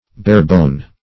Search Result for " barebone" : The Collaborative International Dictionary of English v.0.48: Barebone \Bare"bone`\ (b[^a]r"b[=o]n`), n. A very lean person; one whose bones show through the skin.